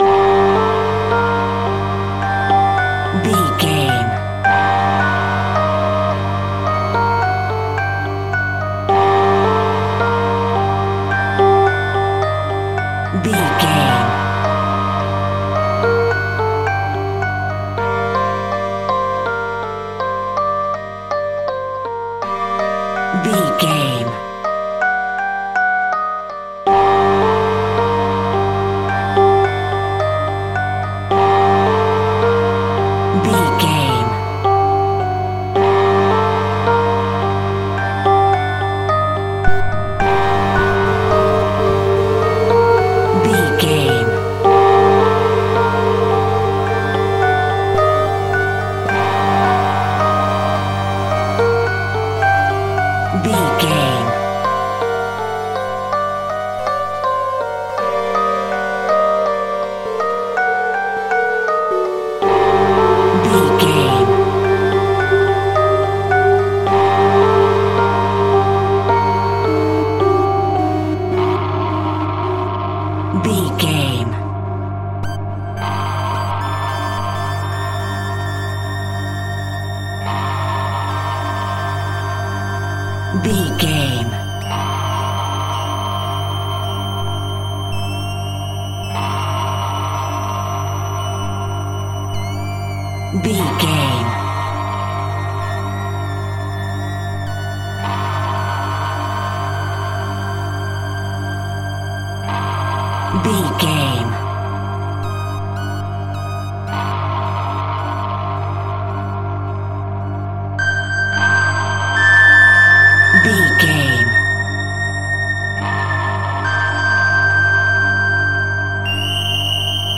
Aeolian/Minor
Slow
scary
tension
ominous
dark
haunting
eerie
electric piano
synthesiser
horror
spooky
Synth Pads
atmospheres